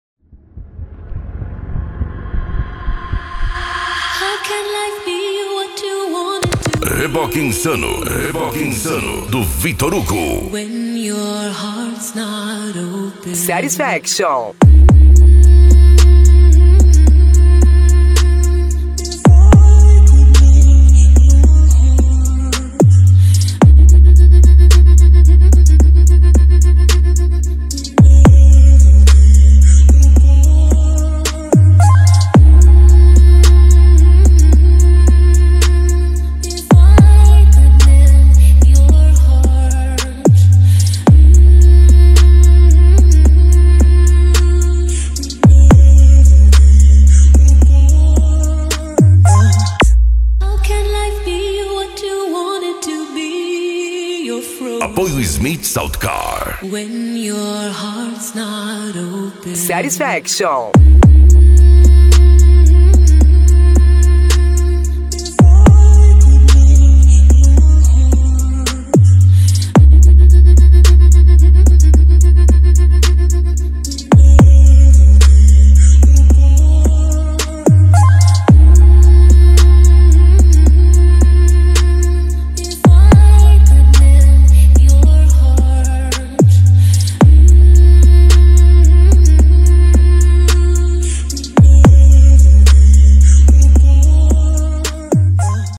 Bass
Funk